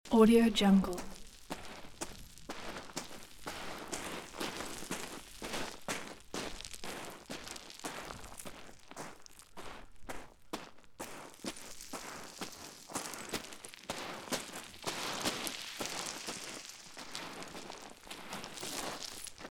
دانلود افکت صوتی راه رفتن روی شن های لغزنده